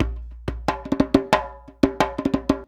089DJEMB07.wav